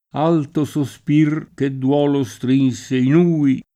uhi [2i] o hui [id.] escl. — es.: Alto sospir, che duolo strinse in «uhi!» [